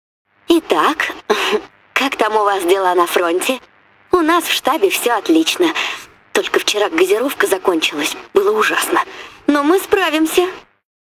В звуковых файлах удалось обнаружить оформление работы (перемещение, выстрелы, применение умений и т.д.) всех новых юнитов.
MechGunner_Shooter_030.ogg